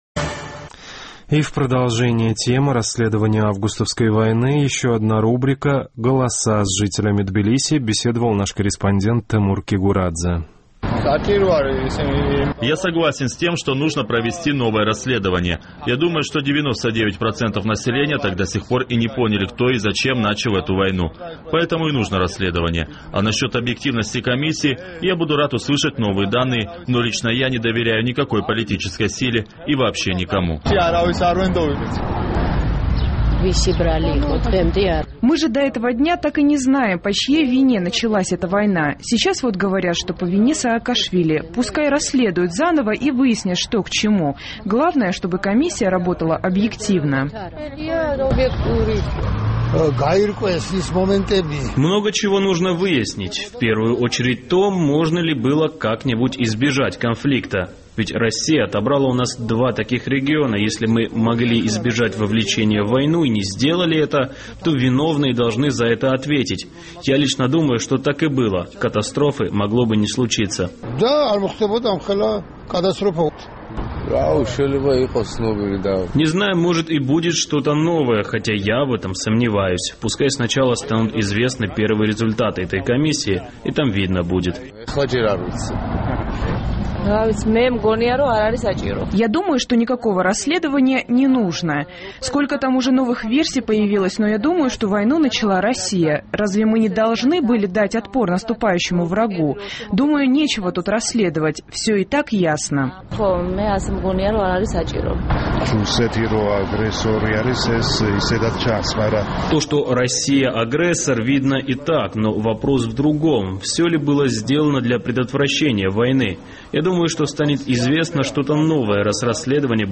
Необходимо ли новое расследование событий 2008 года – мнением жителей Тбилиси интересовался наш корреспондент.